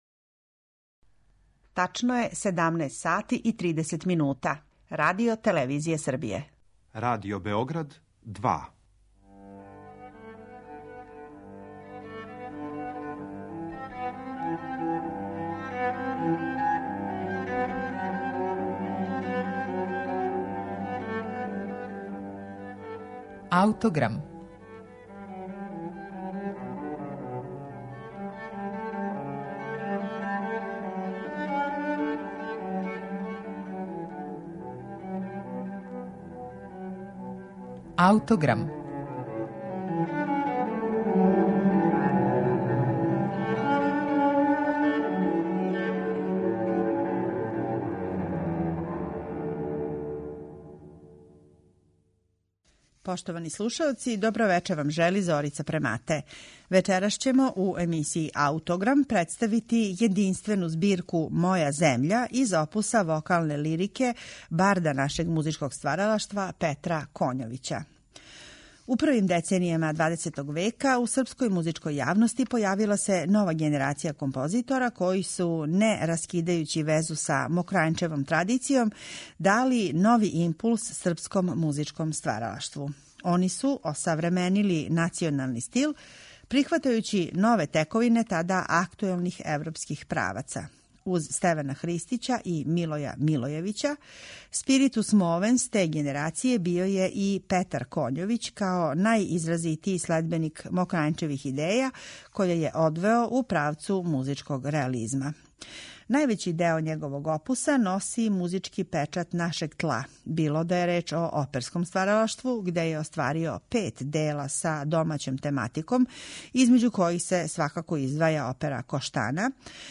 сопран
Емитујемо снимак са компакт-диска који је